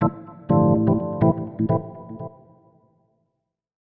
ORGAN005_VOCAL_125_A_SC3(R).wav
1 channel